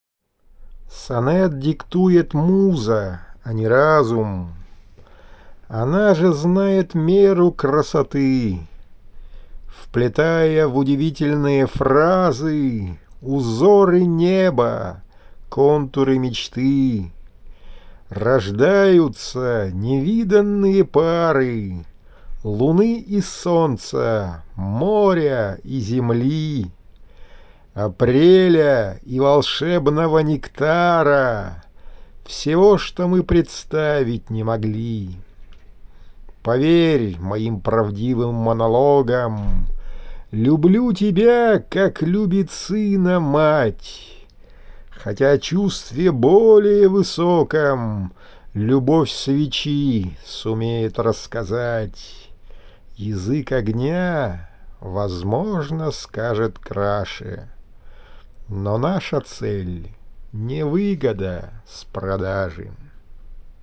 • Жанр: Декламация